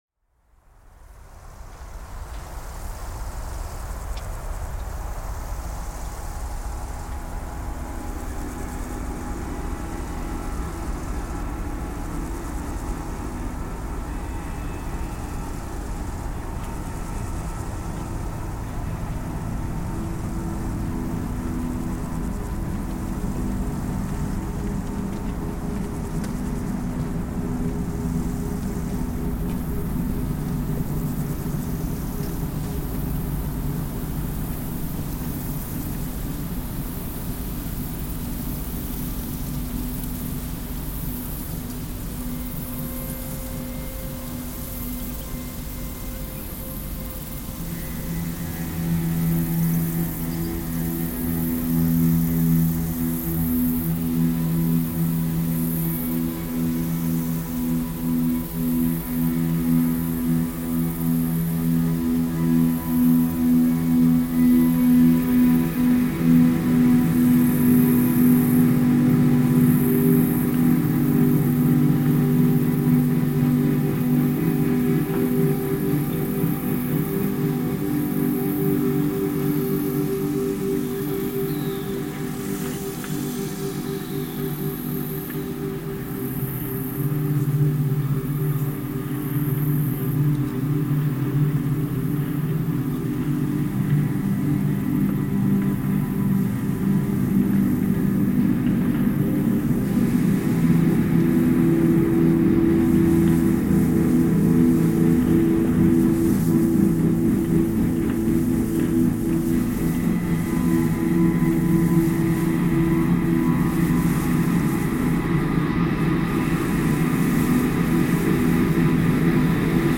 Belgian border post reimagined